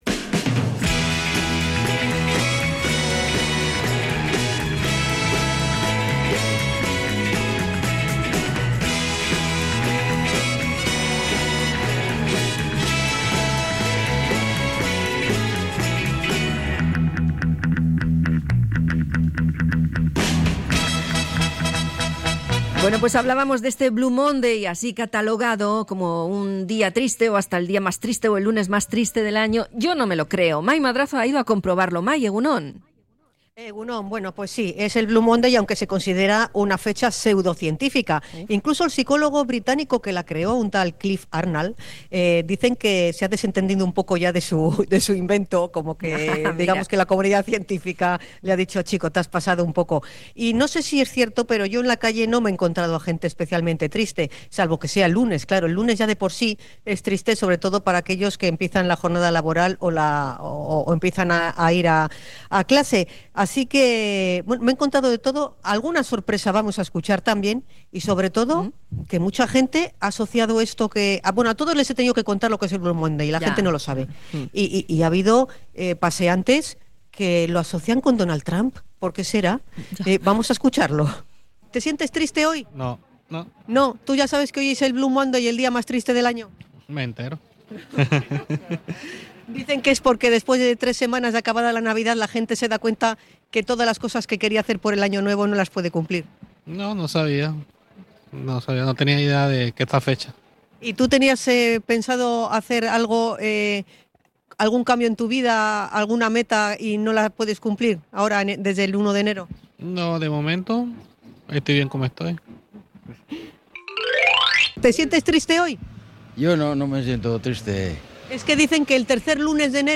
Salimos a la calle para conocer si la gente se siente hoy más triste